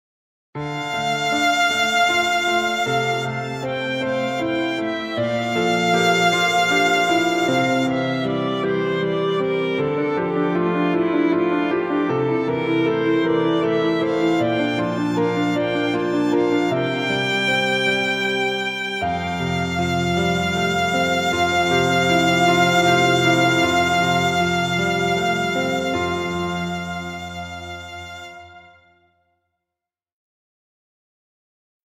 2015.11 | 00:31 | 悲しい | ピアノ+ソロ